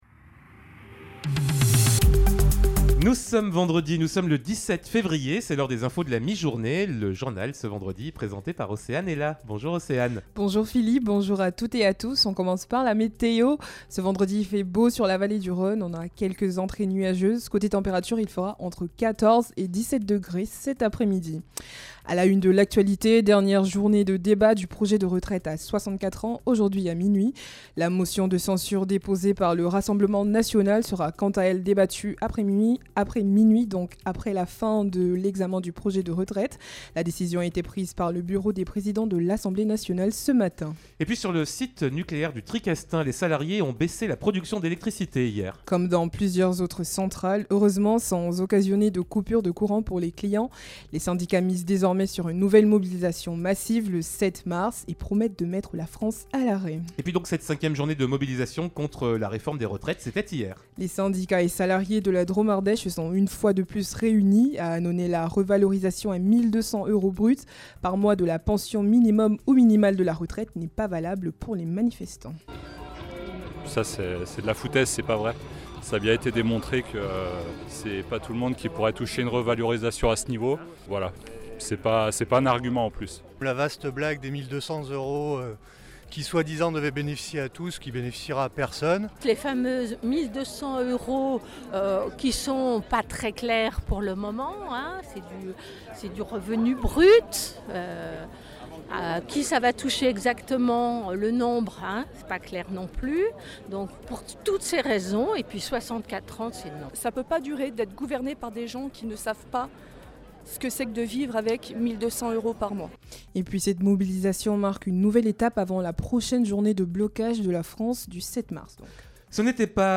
Vendredi 17 février: le journal de 12h